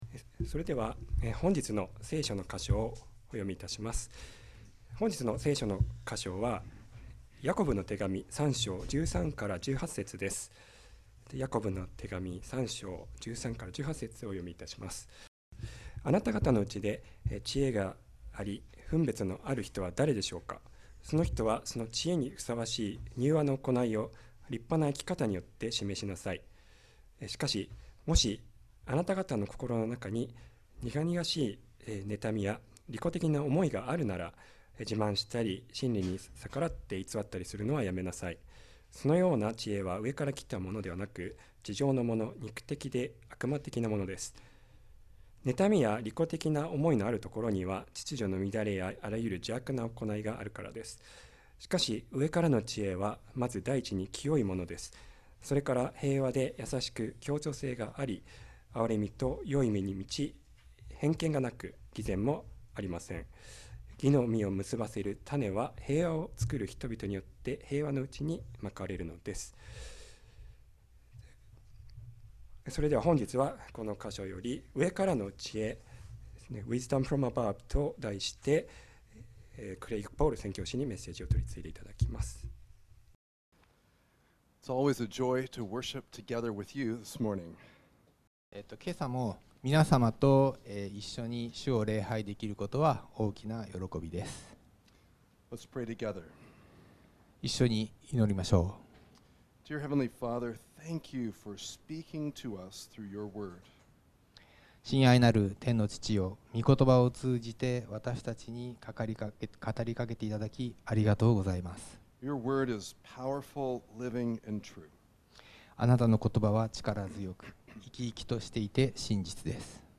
聖書の話 Sermon